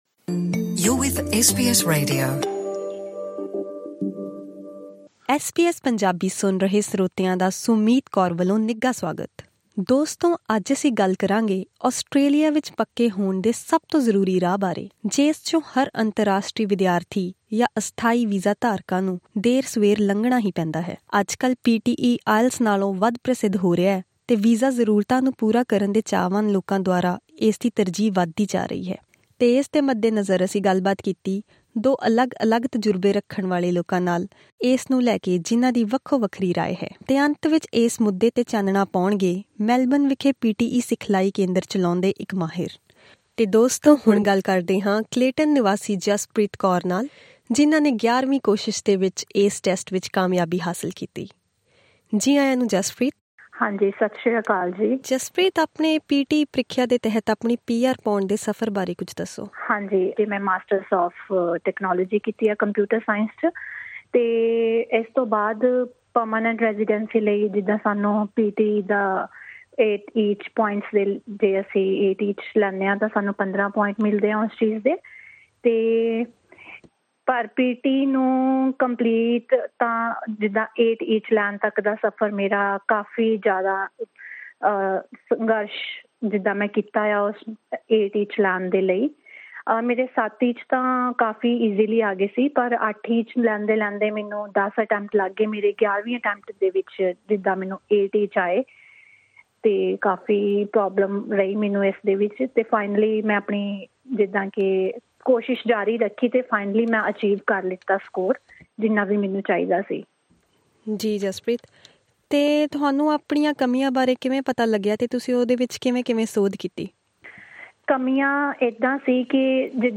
SBS Punjabi spoke to experts to understand what it takes.
pte-all-interviews_compressed.mp3